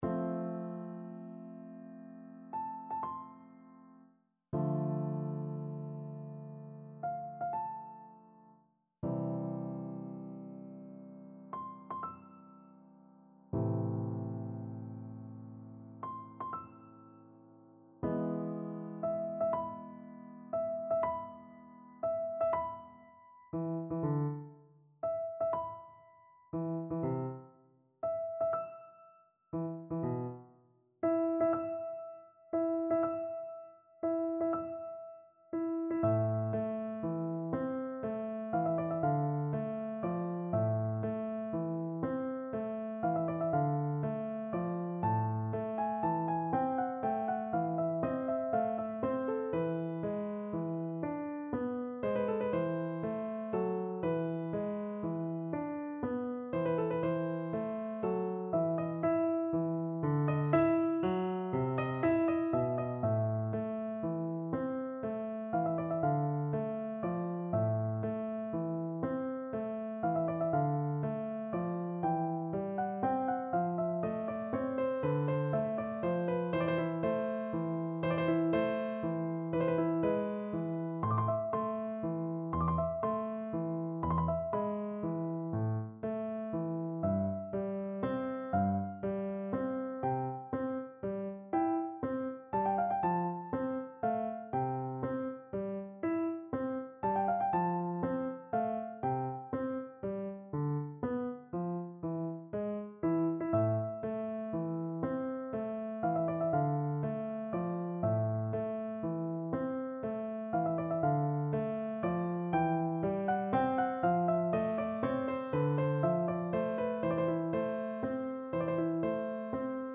Classical Bizet, Georges Symphony in C, 2nd Movement (Adagio) Piano version
No parts available for this pieces as it is for solo piano.
C major (Sounding Pitch) (View more C major Music for Piano )
Adagio
9/8 (View more 9/8 Music)
Piano  (View more Intermediate Piano Music)
Classical (View more Classical Piano Music)